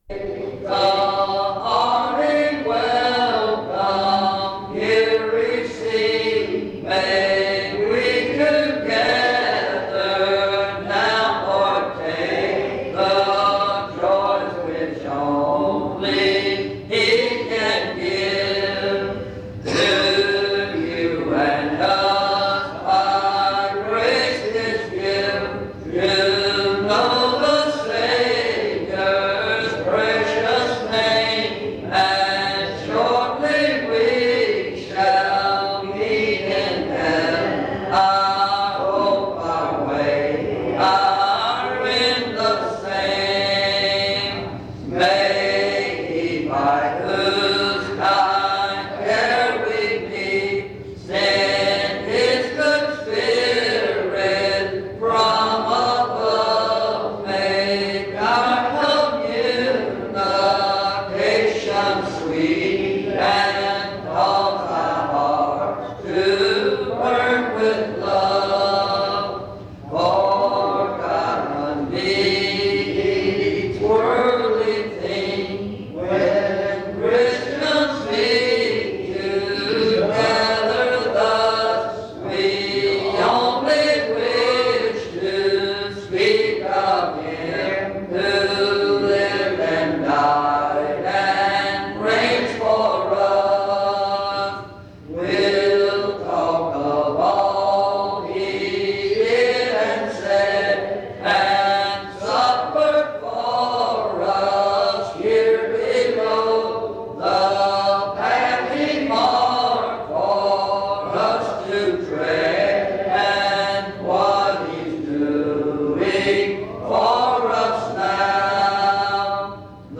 In Collection: Reidsville/Lindsey Street Primitive Baptist Church audio recordings Thumbnail Titolo Data caricata Visibilità Azioni PBHLA-ACC.001_013-A-01.wav 2026-02-12 Scaricare PBHLA-ACC.001_013-B-01.wav 2026-02-12 Scaricare